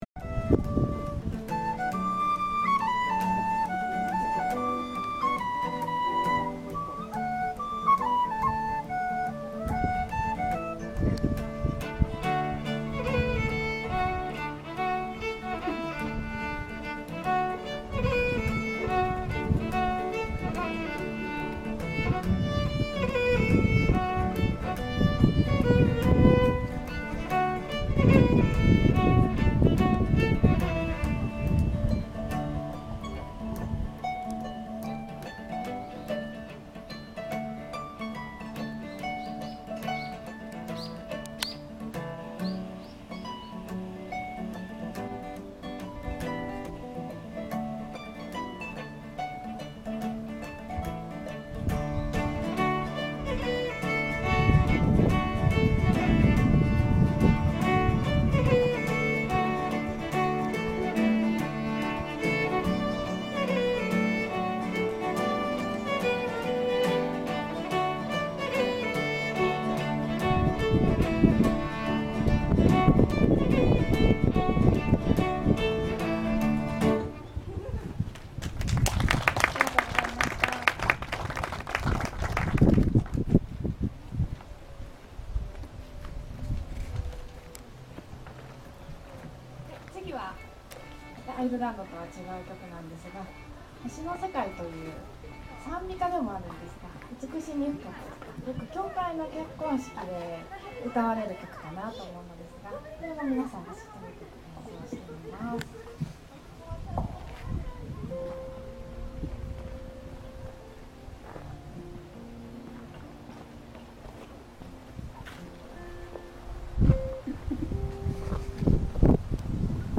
時代は 20 世紀初頭であるがアイルランドとイギリスの紛争を扱っていたことを思い出してアイルランドの音楽を鑑賞に明治村まで出かけた記憶が蘇った。
koyouconcert.mp3